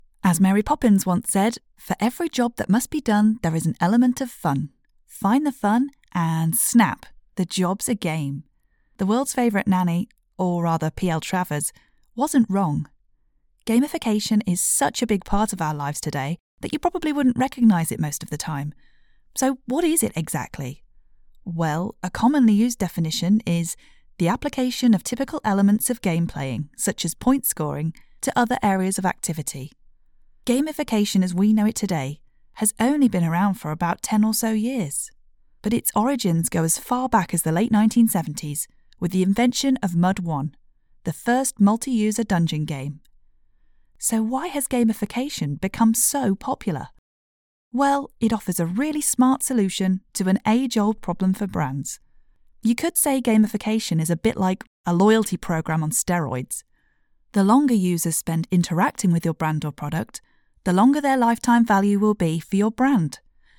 A natural, relatable and versatile voice you can trust!
Sprechprobe: eLearning (Muttersprache):
She has a neutral British accent that is soothing, clear, friendly and most importantly, versatile.